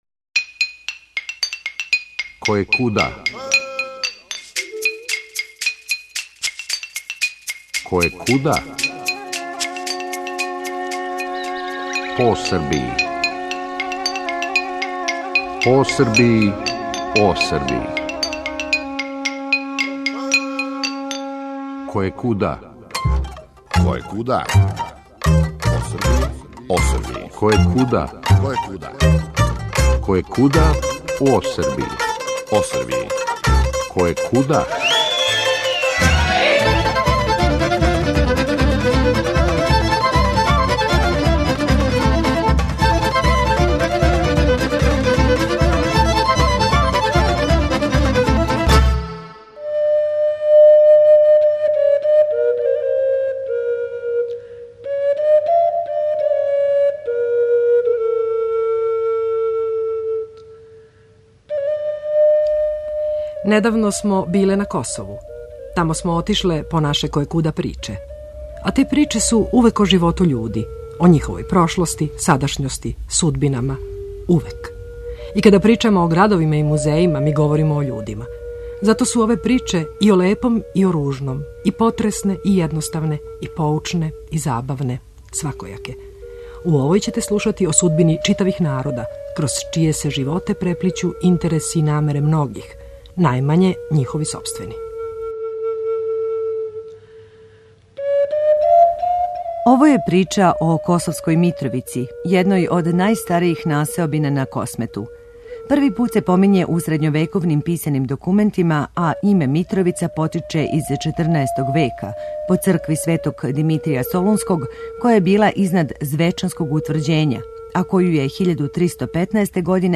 Причале смо и са једнима и са другима...Забележиле смо заиста упечатљиву слику живота града у овом тренутку..